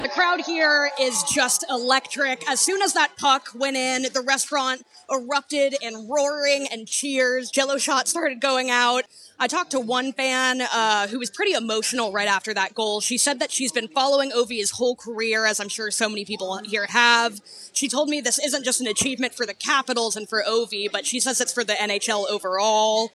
speaks to Capitals fans at The Wharf about their reaction to Alex Ovechkin's record-breaking goal.
The Washington Capitals hosted a Gr8 Chase Watch Party at Whitlow’s at the Wharf on Sunday.